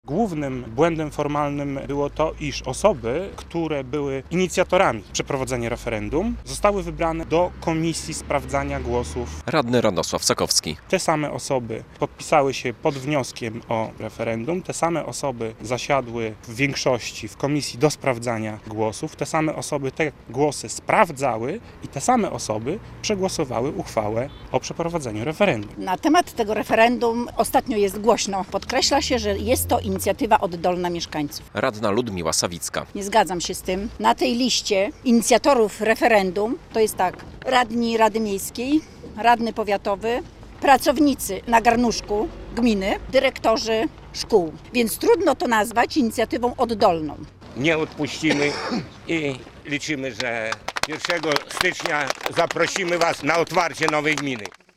Konferencja prasowa zwolenników podziału gminy Supraśl - relacja